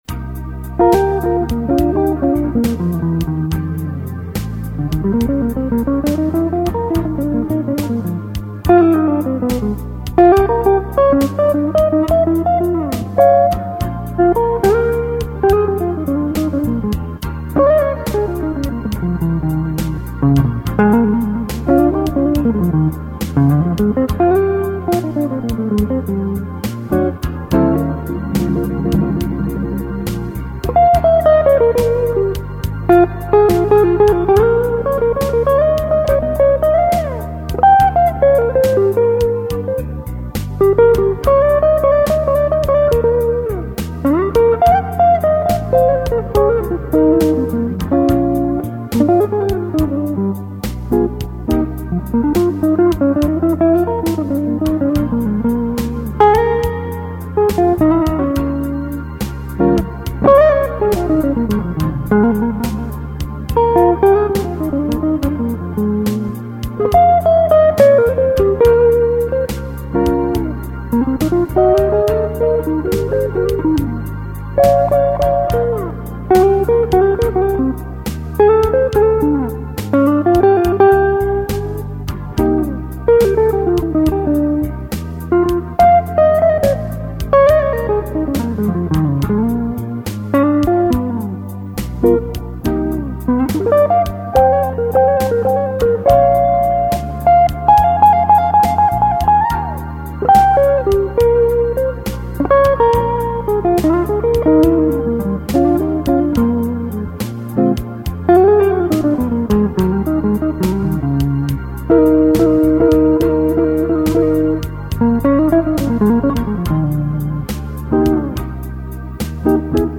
Start with I-IV-V progressions to keep things as simple as possible, although this octave chorus from my "Kitchen Sink Blues" solo will give you an idea of what's possible at that tempo with jazzier changes and using call & response chord punches.
Note that in keeping with the Wes tradition but from a more R&B standpoint, the octave solo is set up by a series of choruses that feature a mix of single-lines, double-stops, and chord punches.
You've got a great feel for the blues.